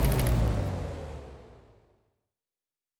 Engine 4 Stop.wav